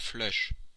Ääntäminen
Synonyymit couleur Ääntäminen France (Normandie): IPA: /flœʃ/ Tuntematon aksentti: IPA: /flɔʃ/ Haettu sana löytyi näillä lähdekielillä: ranska Käännös Konteksti Substantiivit 1. flush pokeri Suku: m .